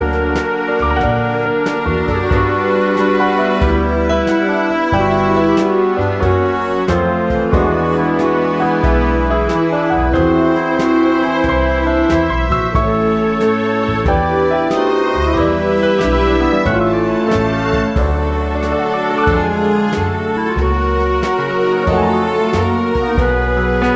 Without Choir Oldies (Female) 2:58 Buy £1.50